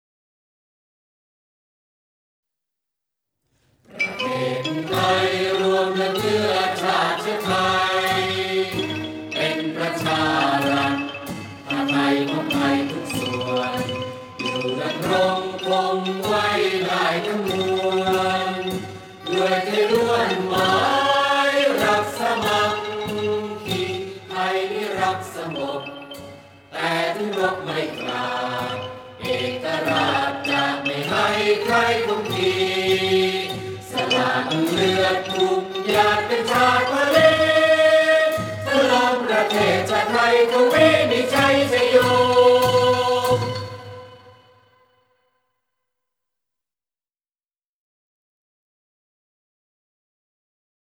เพลงชาติ (วงปี่พาทย์-ไม้แข็ง)